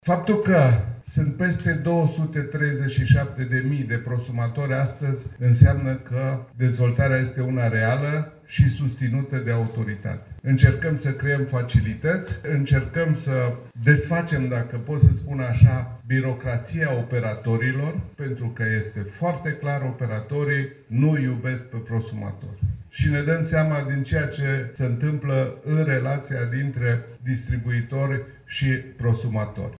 Conferința SMART ENERGY 2025 organizată azi la Constanța a adus în prim plan energia și, mai precis, viitorul energiei inteligente între inovație, digitalizare și eficiență.